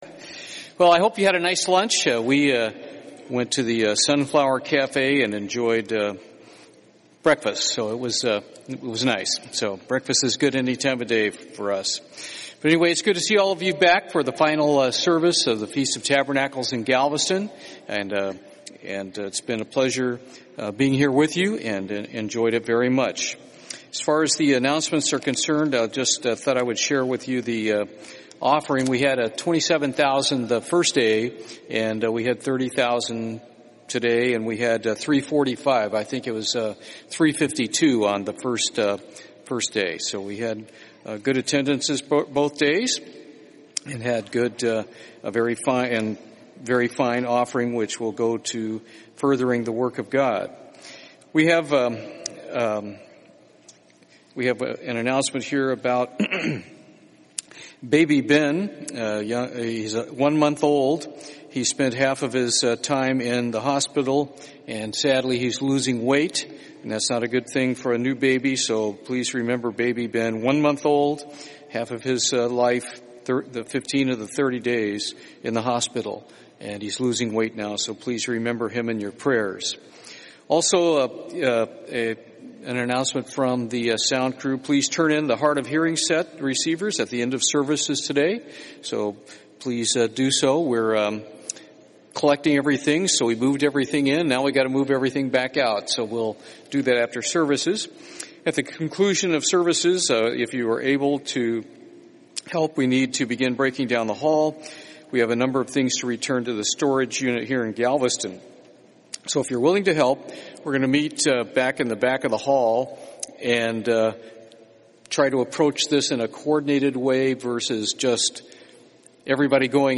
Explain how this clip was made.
This sermon was given at the Galveston, Texas 2018 Feast site.